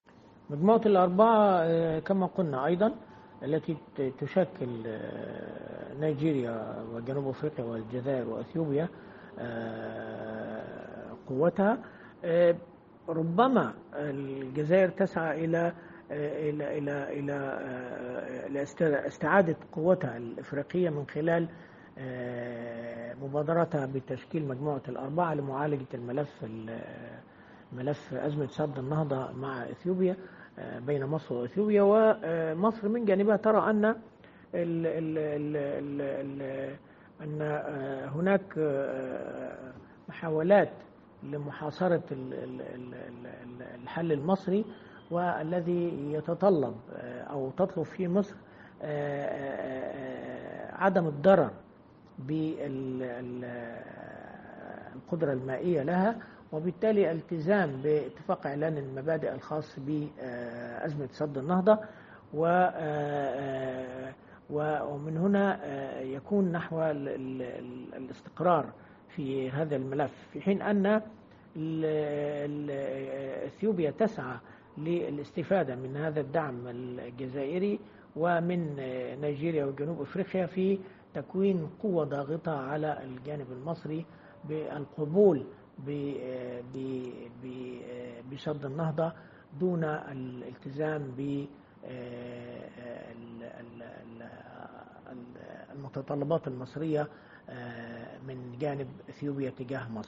الكاتب الصحفي والمحلل السياسي